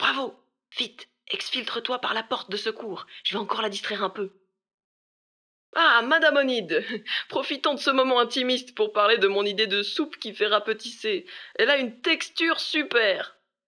VO_LVL3_EVENT_Bravo reussite mission_01.ogg